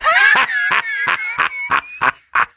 sam_lead_vo_06.ogg